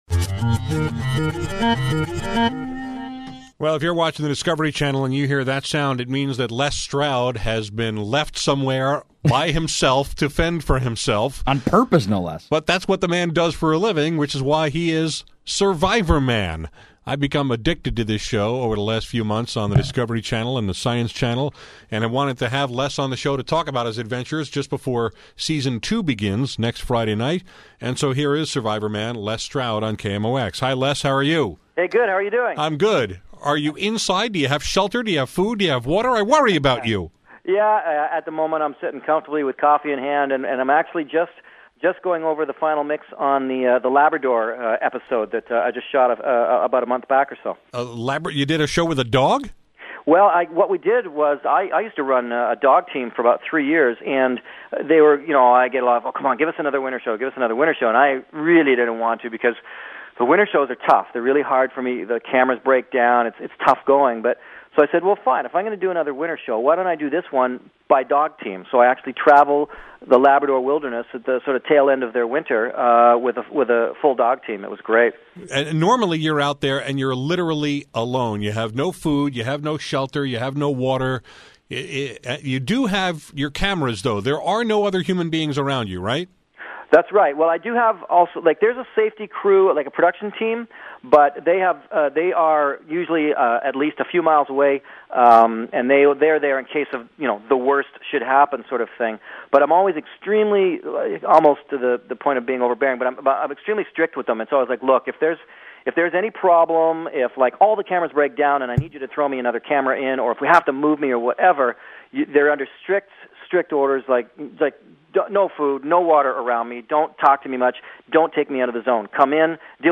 This afternoon on my show, I talked with Les Stroud, whose “Survivorman” series has become must-see TV in my house.